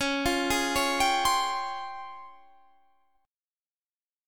Listen to C#M#11 strummed